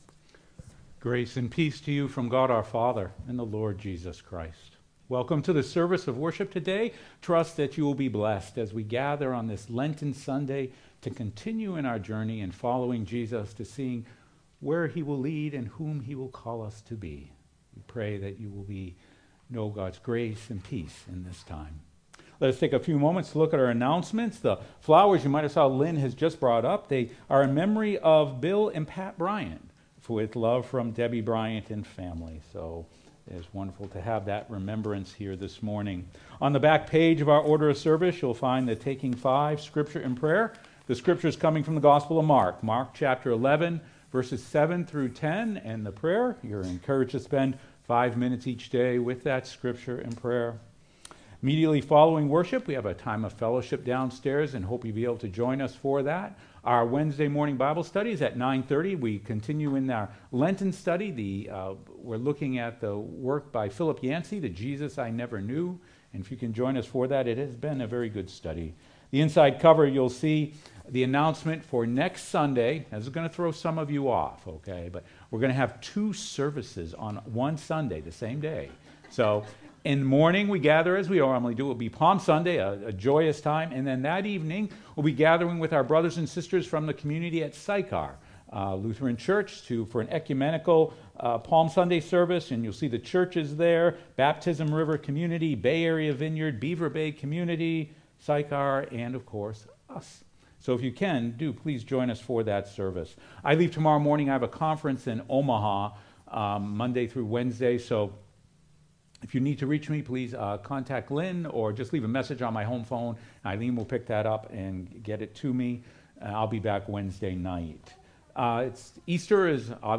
sermon-2.mp3